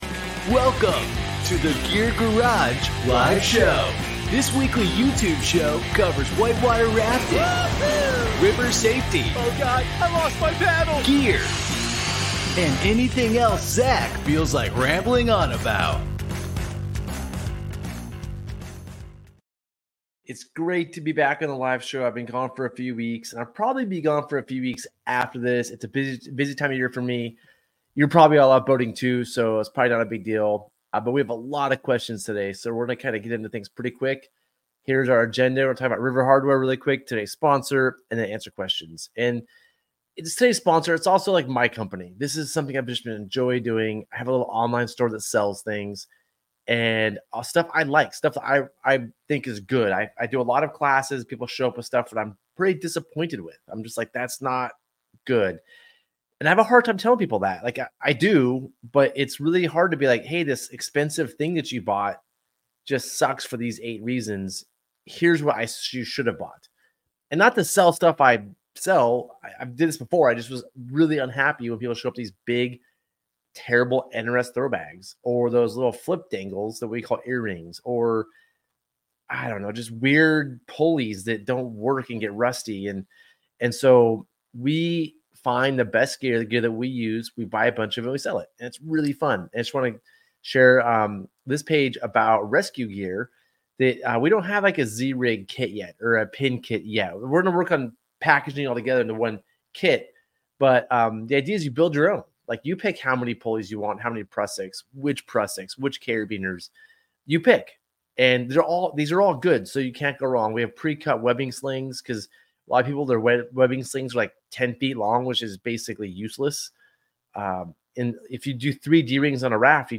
Gear Garage Live Show Gear Garage Live Show | July 2nd, 2024 Jul 03 2024 | 00:47:33 1x 00:00 / 00:47:33 Subscribe Share Spotify RSS Feed Share Link Embed